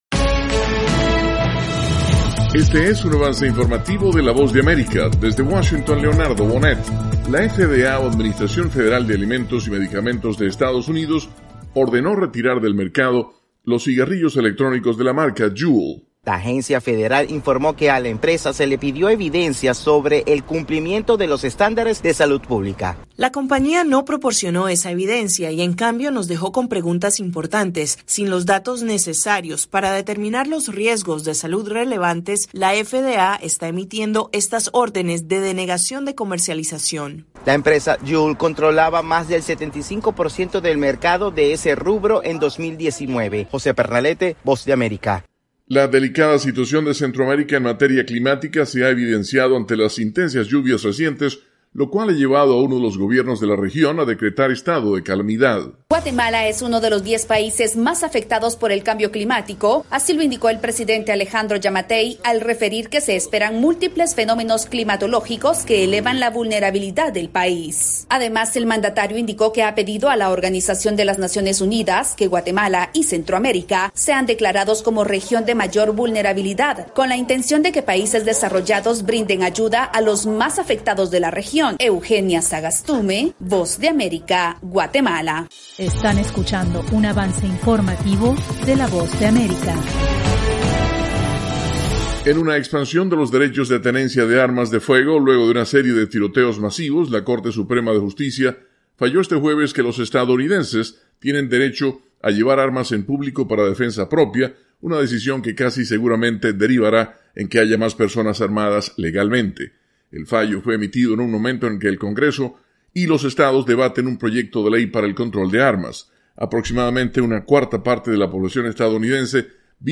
Avance Informativo - 7:00 PM
El siguiente es un avance informativo presentado por la Voz de América, desde Washington